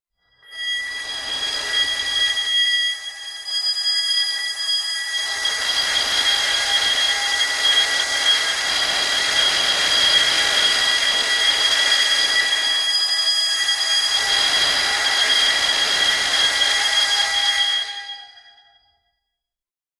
an intense, anxiety-inducing horror game sound effect designed to build tension. Include sharp, high-pitched dissonant tones, irregular metallic screeches, sudden low-frequency pulses, and distorted mechanical noises that escalate unpredictably 0:20
an-intense-anxiety-induci-wfmbkcyz.wav